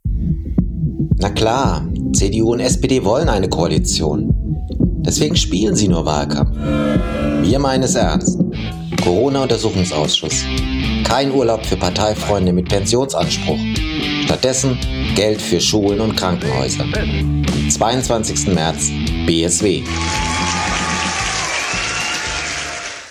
Wahlwerbespots Hörfunk